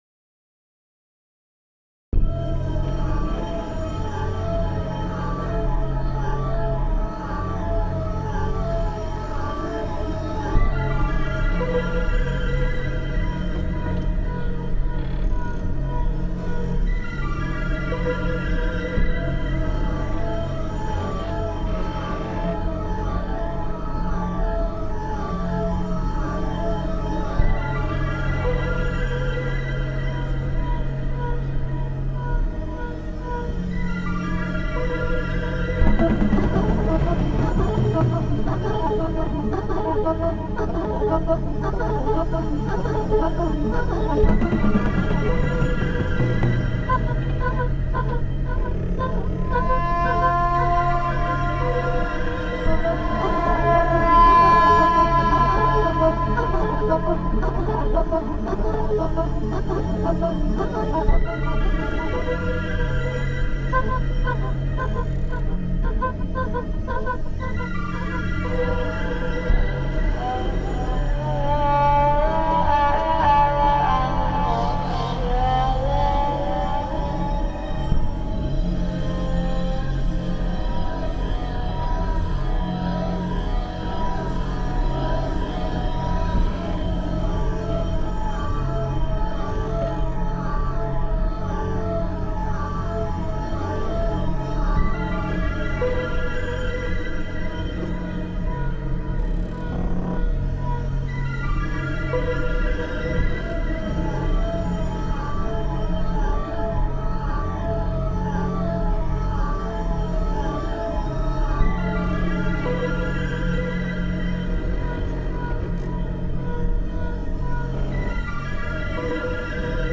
恐怖・ホラー・不気味・気持ち悪い場面にぴったりな不安になるBGMです。